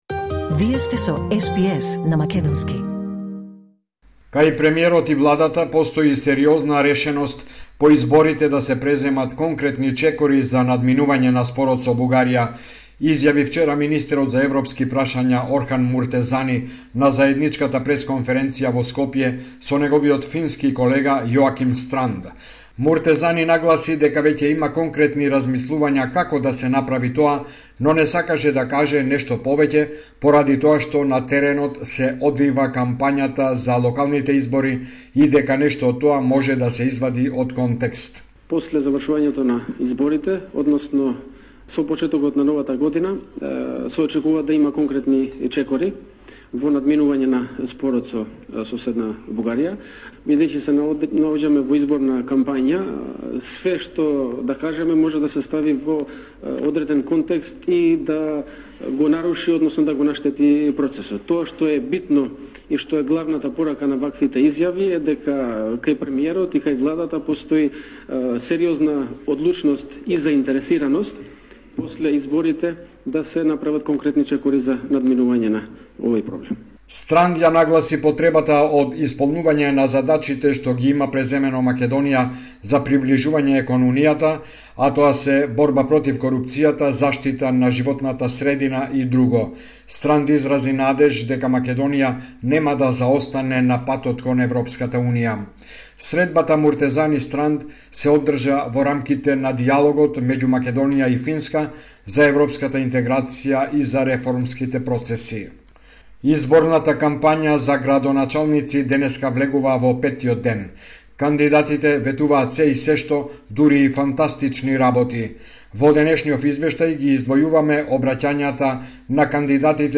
Извештај од Македонија 3 октомври 2025